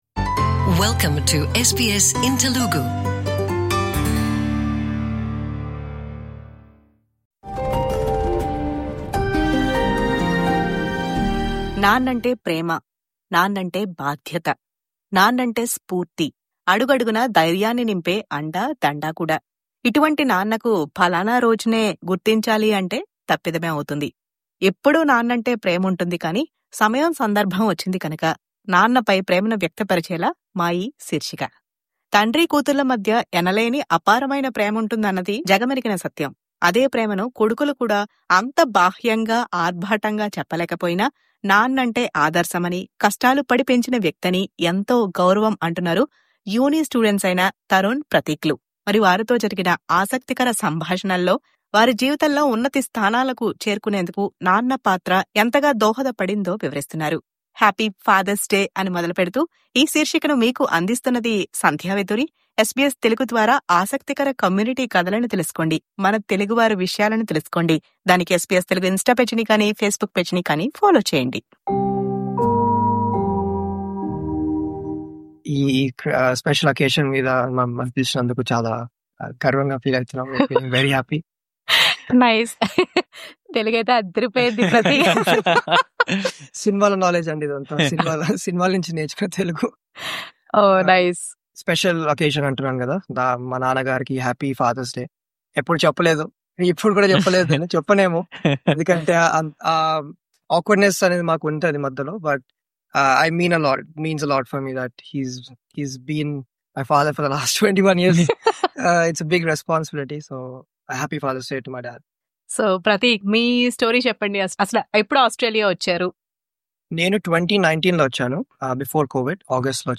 ముఖాముఖి